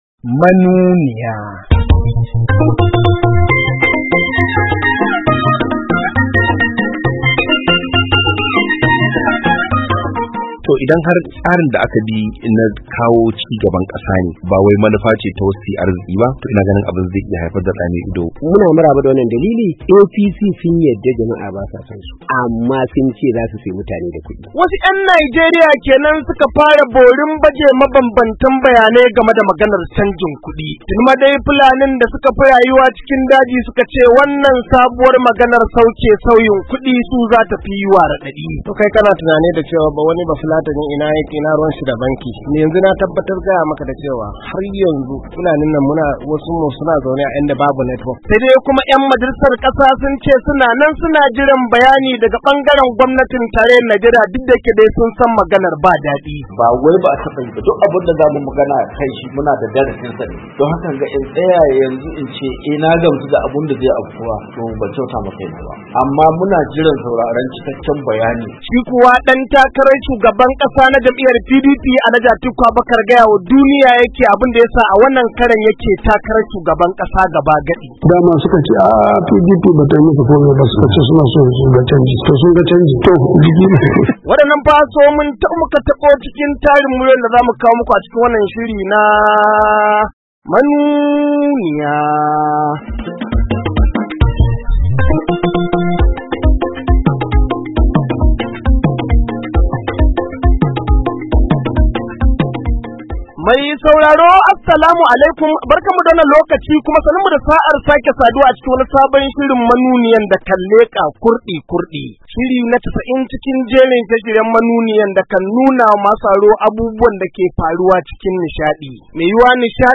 KADUNA, NIGERIA - A cikin shirin na wannan makon mun maida hankali ne kan shirin canjin kudi a Najeriya da kuma hirar dan-takarar shugaban kasa na Jam'iyyar PDP, Alhaji Atiku Abubakar kan batutuwa daban-daban.